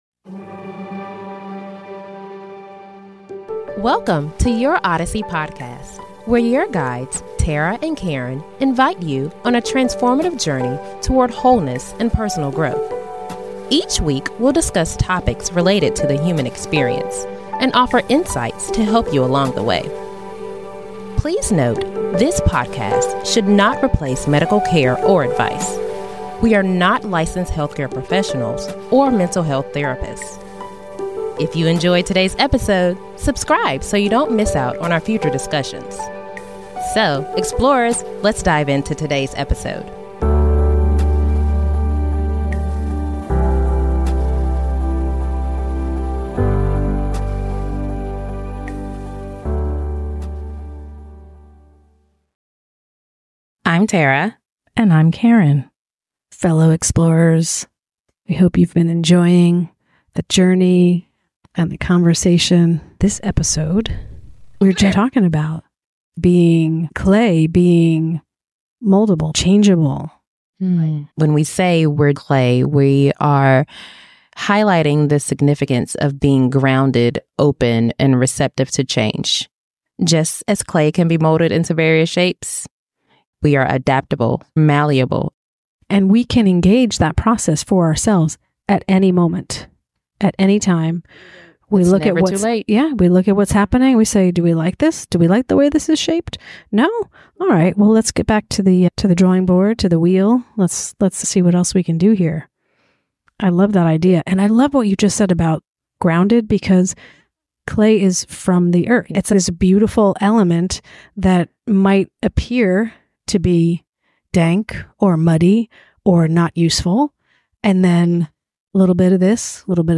Through heartfelt conversations, they discuss the importance of finding beauty in vulnerability, embracing imperfections, and trusting the process of life. As they share personal stories of growth and transformation, listeners are inspired to live authentically, lean into their inner artist, and craft a life filled with joy and purpose.